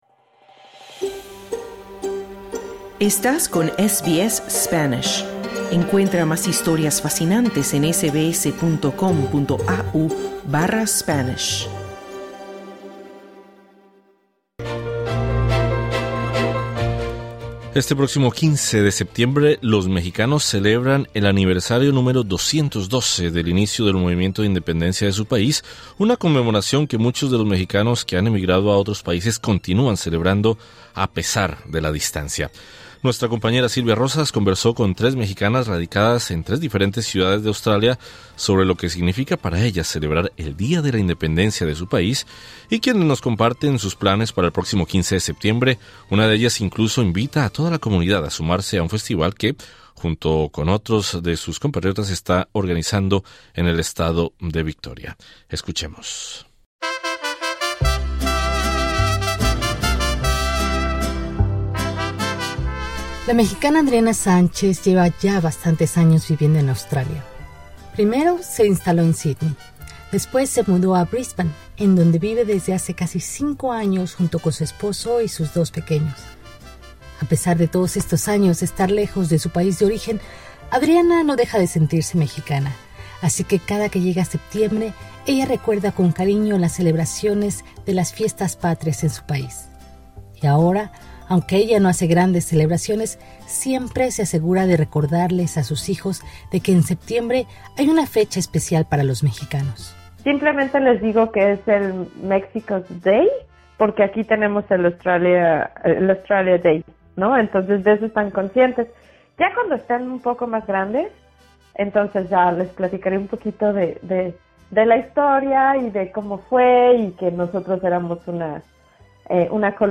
El próximo 16 de septiembre los mexicanos celebrarán el 212 aniversario del inicio del movimiento de independencia de su país. SBS Spanish conversó con tres integrantes de esa comunidad para saber cómo festejarán en Australia.